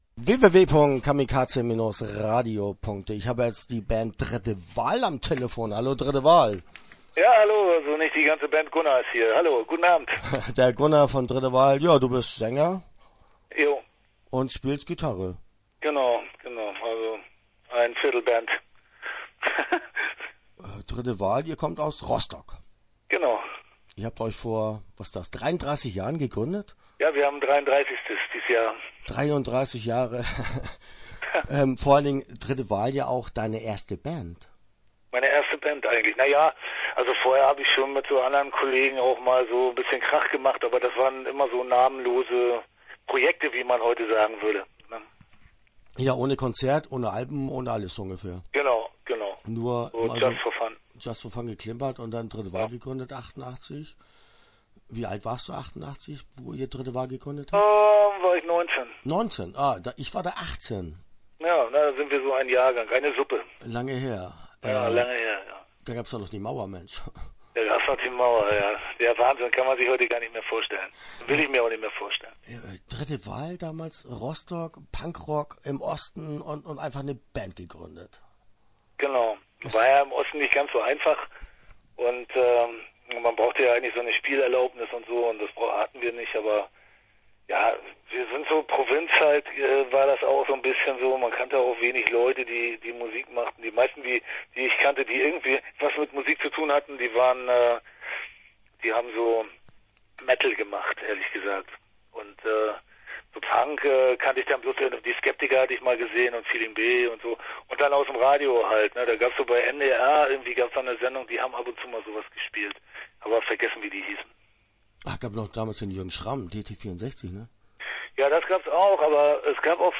Dritte Wahl - Interview Teil 1 (14:54)